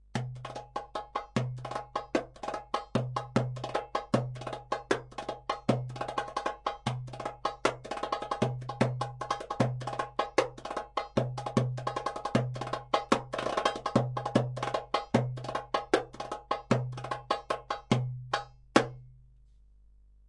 印度 2006 " SN D 2080 在火车站被问及列车号码
描述：在两节火车车厢之间的包厢里等待时，一位列车员问我的车厢号是什么。
标签： 场记录 印地文 印度 火车
声道立体声